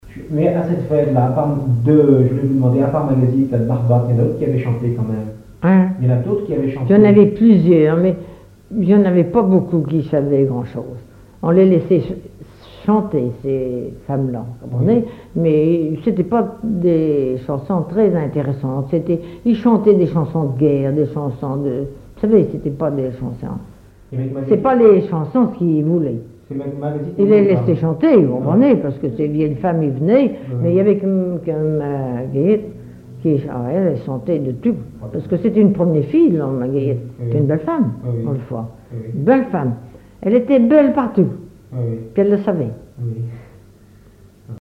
collecte en Vendée
Catégorie Témoignage